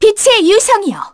Artemia-Vox_Skill3-2_kr.wav